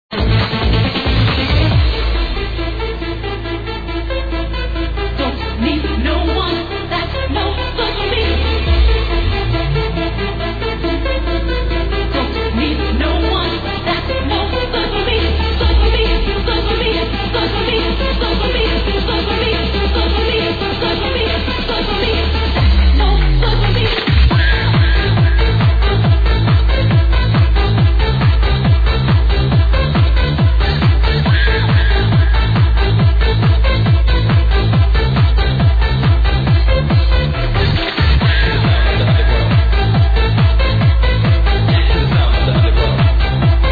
hard trance track